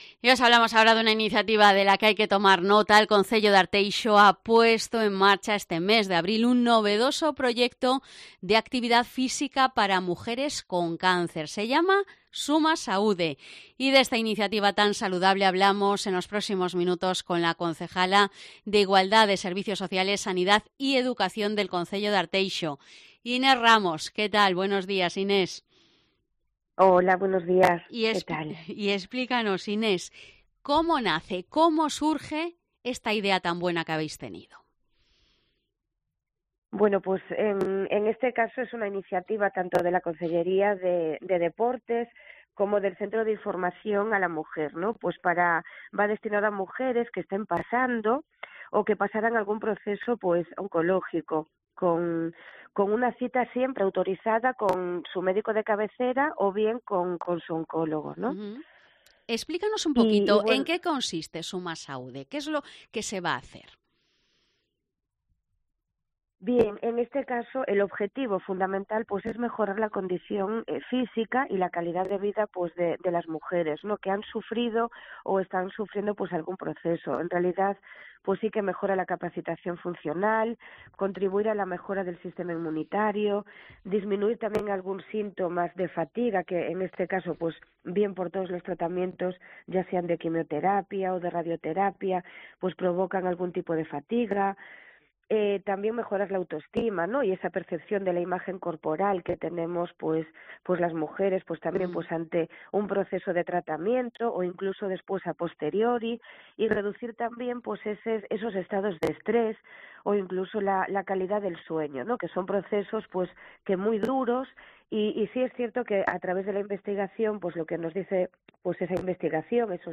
Entrevista a la concejala de Arteixo, Inés Ramos por el programa Suma Saúde